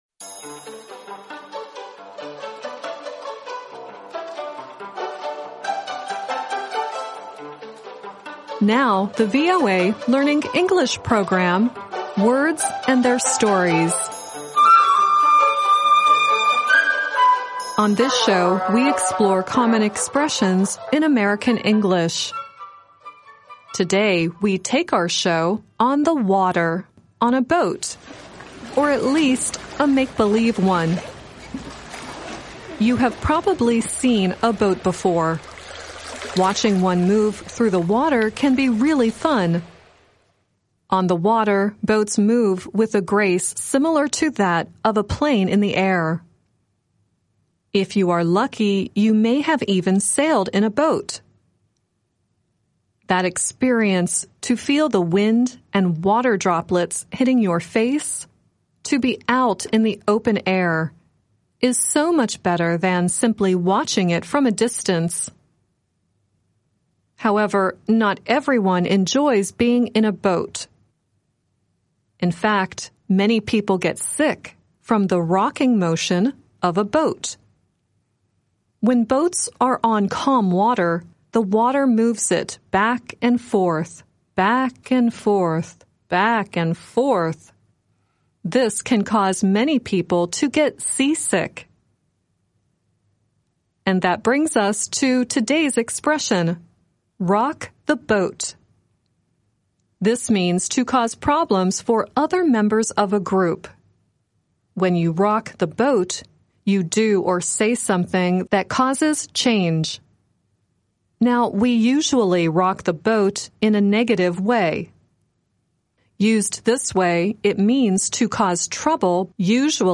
The song at the end is The Hues Corporation singing, "Rock the Boat."